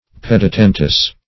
Pedetentous \Ped`e*ten"tous\, a. [L. pes, pedis, foot + tendere